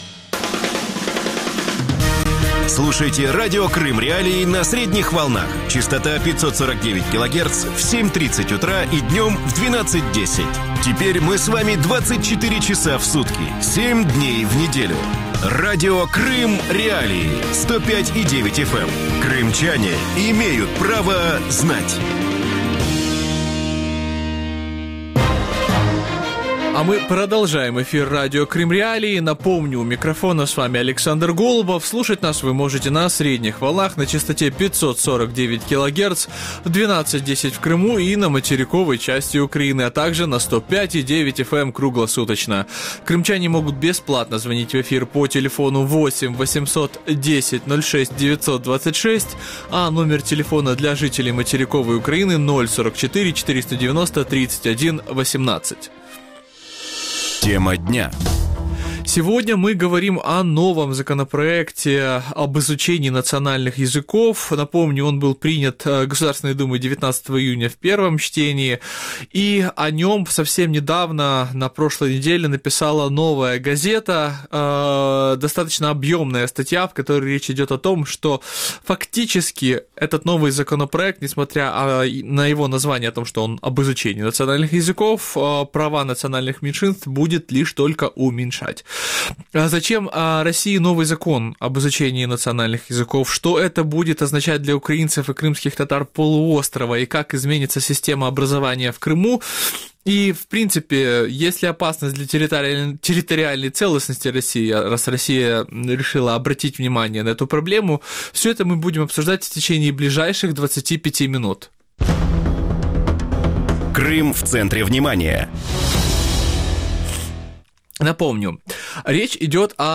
Ответы на эти вопросы ищите с 12:10 до 12:40 в эфире ток-шоу Радио Крым.Реалии